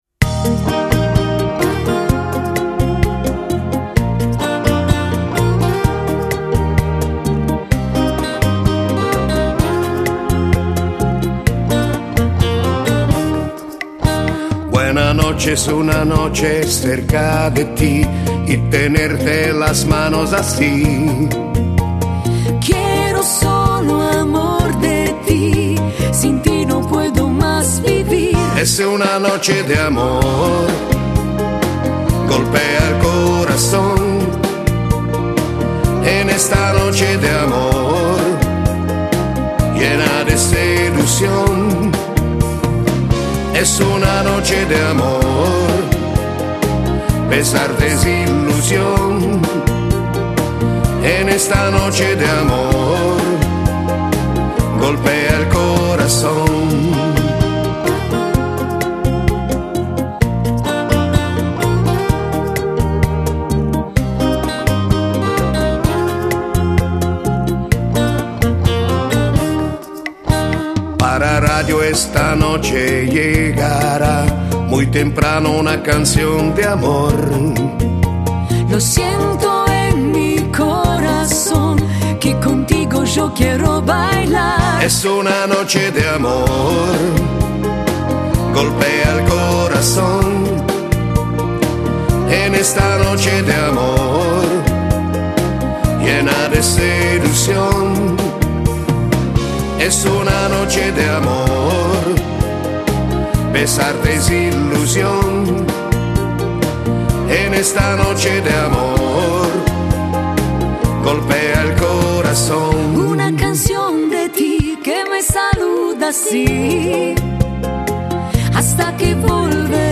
Genere: Bachata
Scarica la Base Mp3 con Cori (4,80 MB)